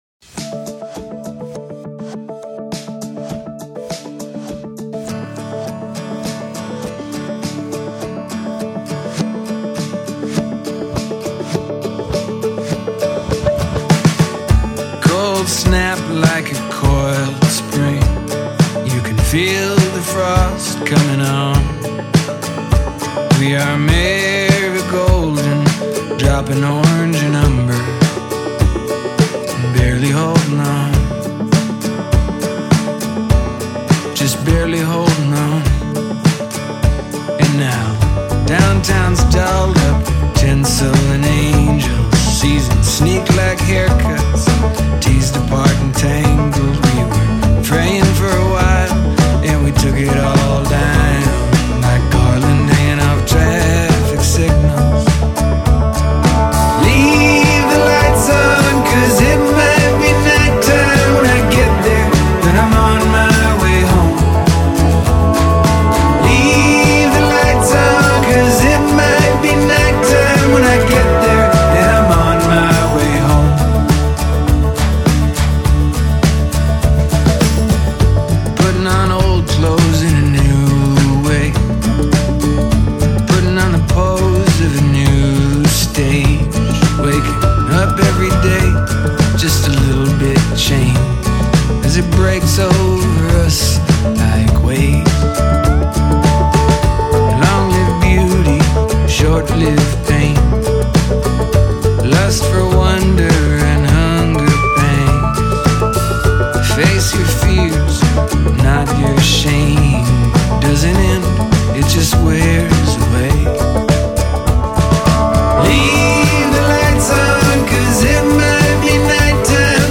country-tinged
pressing rhythms